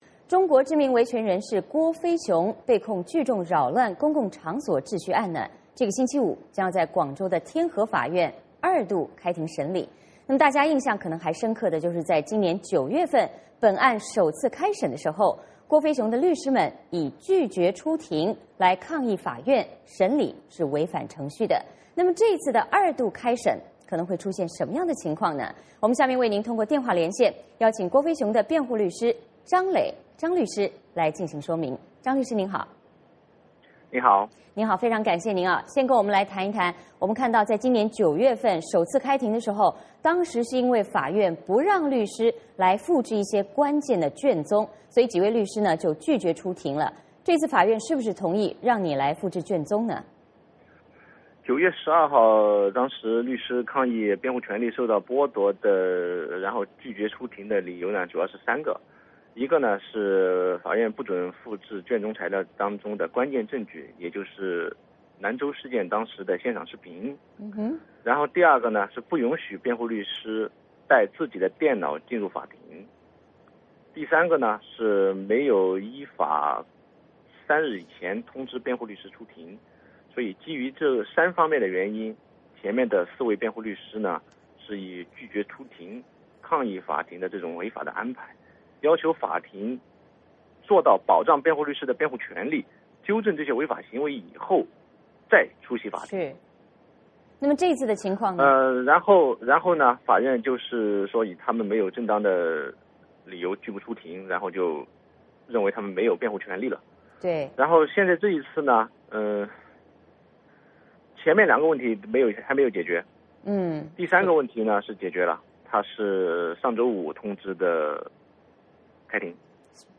VOA连线：郭飞雄案周五再度开审，律师将出庭